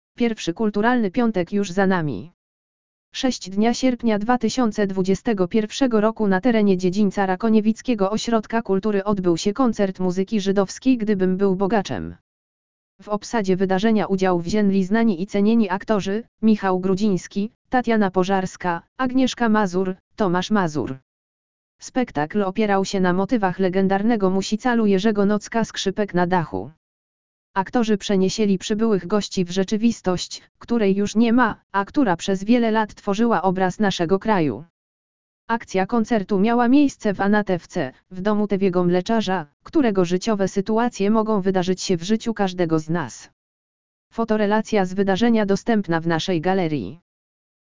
audio_lektor_pierwszy_kulturalny_piatek_juz_za_nami!.mp3